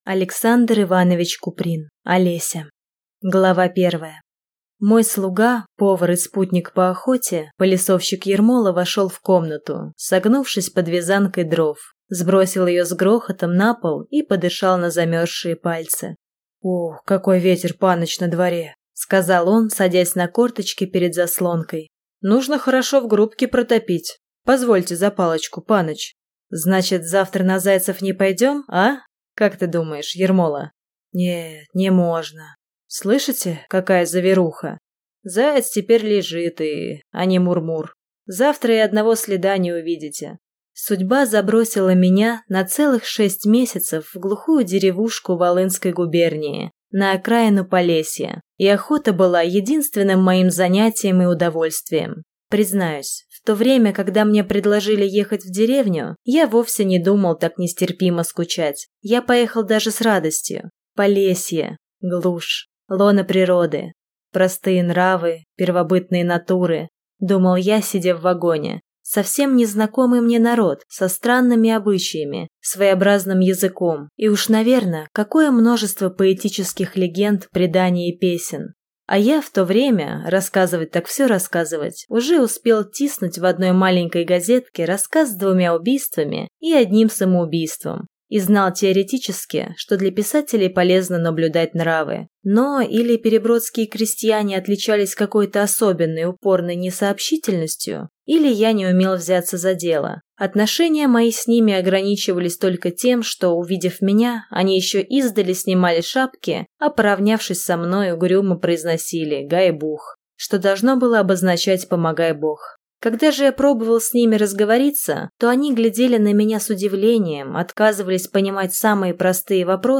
Aудиокнига Олеся